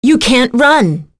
Priscilla-Vox_Skill3_b.wav